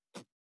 416,ジッパー,チャックの音,洋服関係音,ジー,バリバリ,カチャ,ガチャ,シュッ,パチン,ギィ,カリ,
ジッパー効果音洋服関係